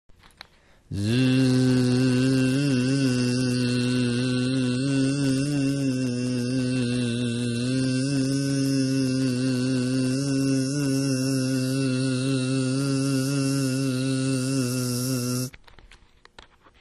蜜蜂 " 蜜蜂嗡嗡声 3
描述：发声模仿蜜蜂的嗡嗡声
Tag: 蜜蜂 声乐 模仿 嗡嗡声